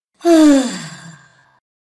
женский вздох разочарования